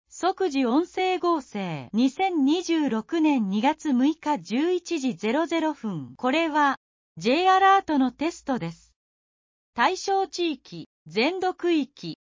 「即時音声合成」 2026年02月06日11時00分 これは、Jアラートのテストです。